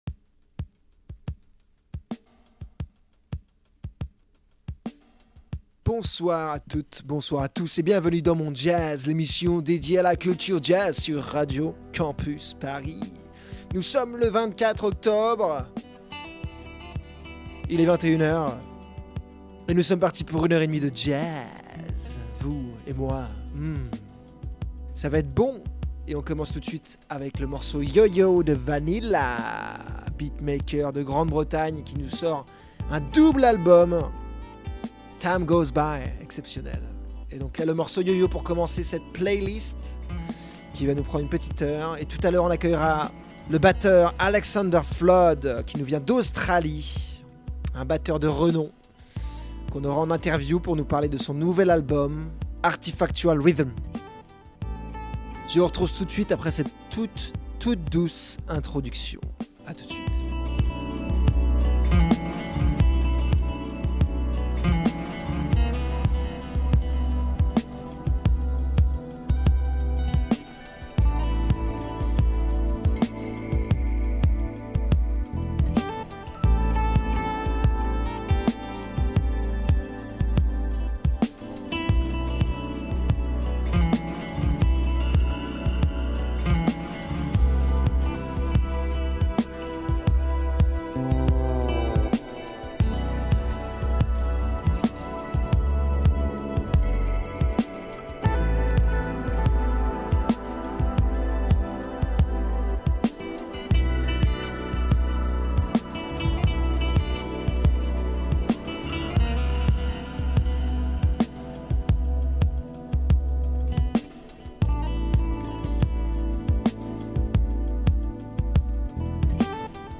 Type Musicale Classique & jazz
En première partie, la playlists des sorties récentes